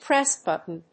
アクセントpréss‐bùtton